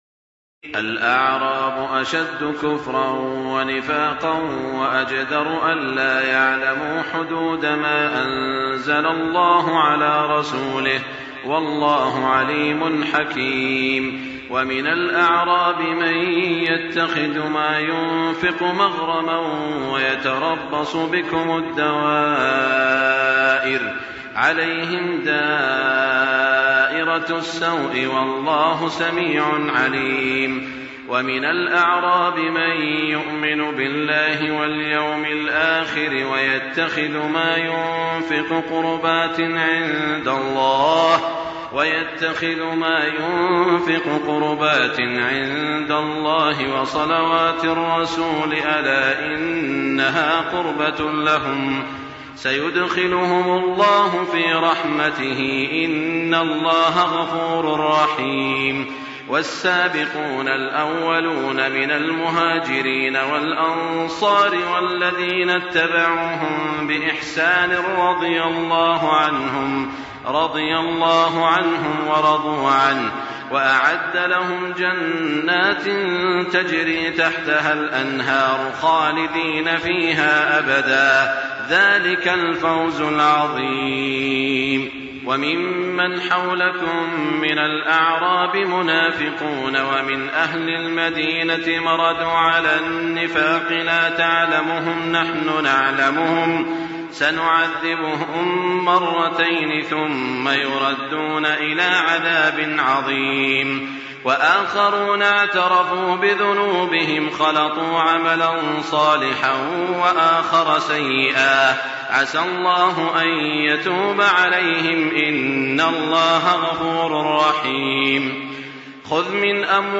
تراويح الليلة العاشرة رمضان 1424هـ من سورتي التوبة (97-129) و يونس (1-25) Taraweeh 10 st night Ramadan 1424H from Surah At-Tawba and Yunus > تراويح الحرم المكي عام 1424 🕋 > التراويح - تلاوات الحرمين